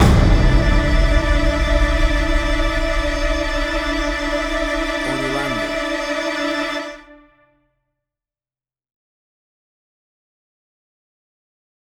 A dark and scary Horror Hit!
WAV Sample Rate: 16-Bit stereo, 44.1 kHz